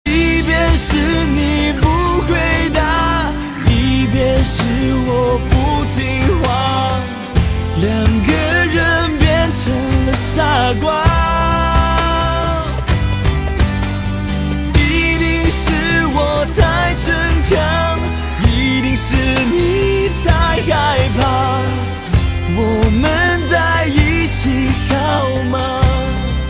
M4R铃声, MP3铃声, 华语歌曲 43 首发日期：2018-05-15 07:41 星期二